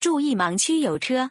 audio_car_watchout.wav